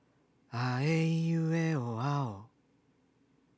地声の機能があまり働いていない喋り声
音量注意！